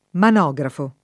manografo [ man 0g rafo ]